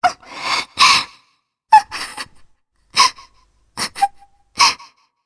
Shea-Vox_Sad_jp.wav